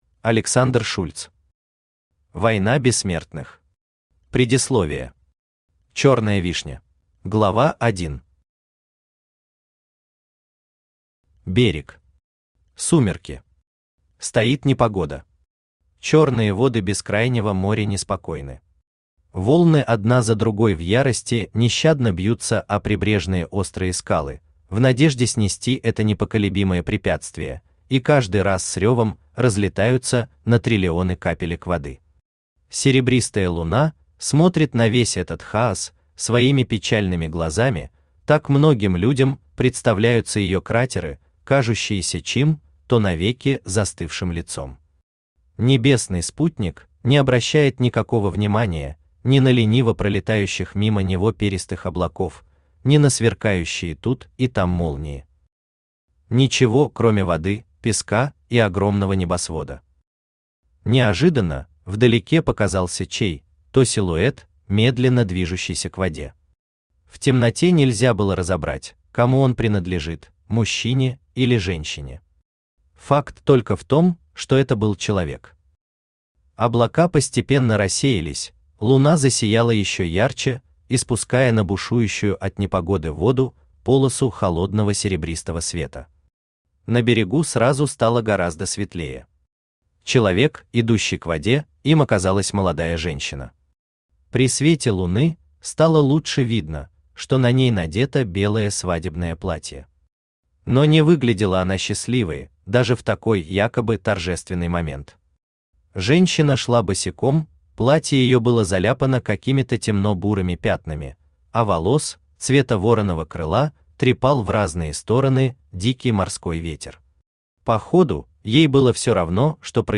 Аудиокнига Война Бессмертных. Предисловие. Черная Вишня | Библиотека аудиокниг
Черная Вишня Автор Александр Шульц Читает аудиокнигу Авточтец ЛитРес.